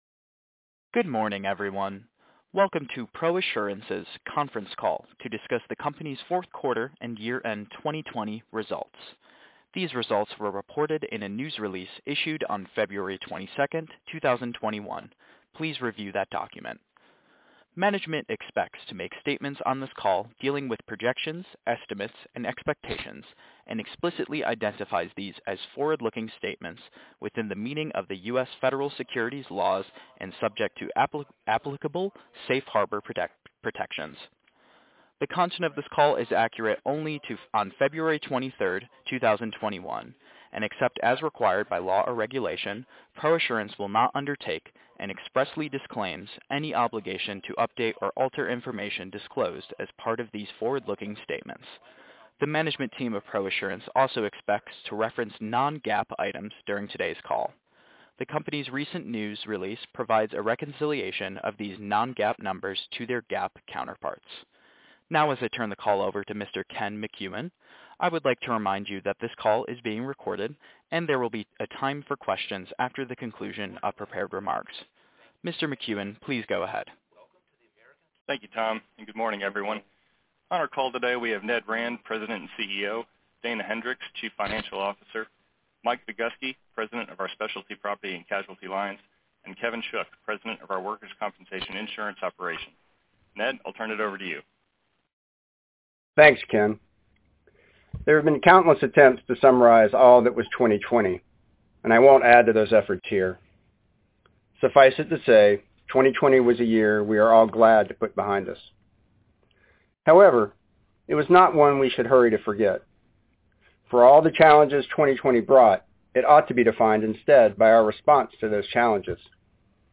Q4 Earnings Conference Call | ProAssurance Corporation